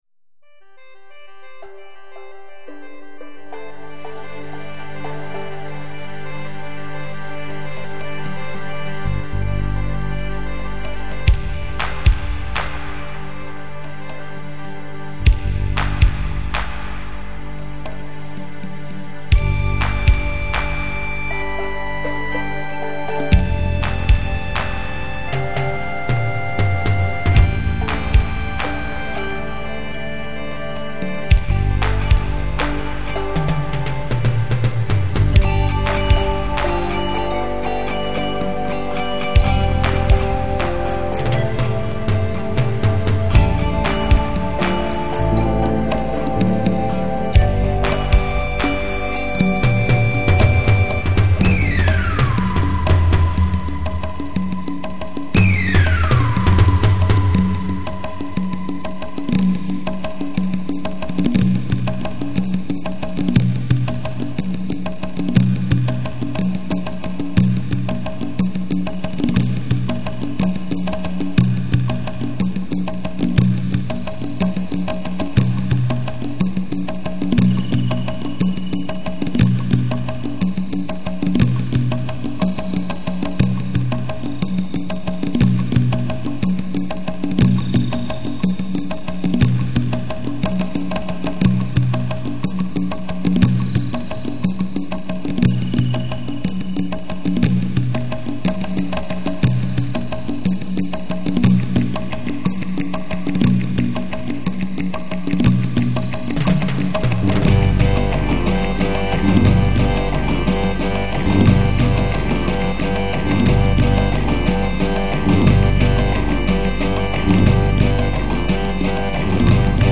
Musiche di scena dall'opera teatrale
Sonorizzazioni dal vivo